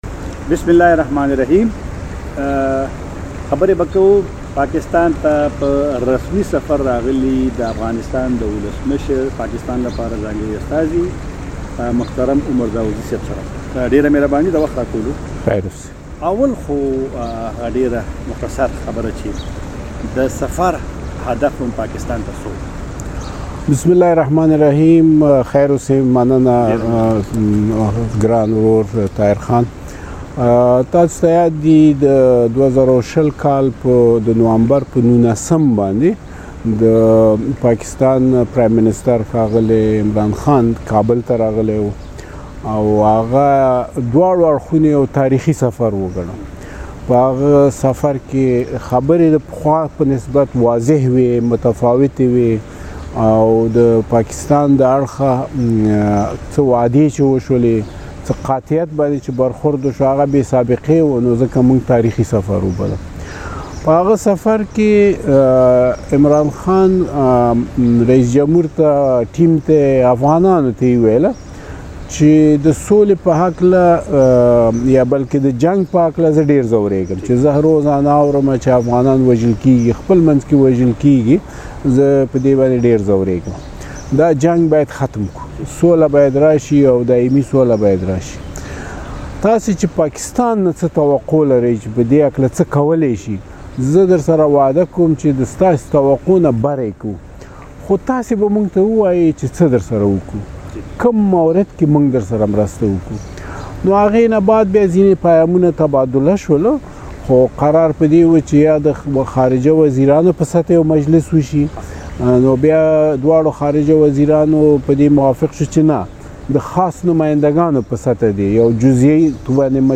عمر داوودزي دا څرګندونې د فبرورۍ پر ۲۶مه، په اسلام اباد کې له مشال راډيو ته په یوې ځانګړې مرکې کې وکړې.
له داودزي سره مرکه دلته واورئ